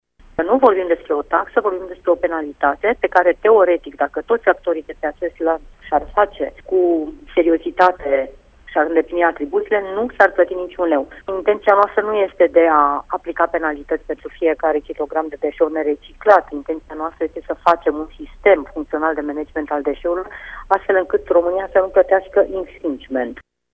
Secretarul de stat a subliniat că este vorba despre o penalizare care se aplică producătorilor care nu reușesc să recicleze ambalajele, pentru ca România să evite declanșarea procedurii de infrigment.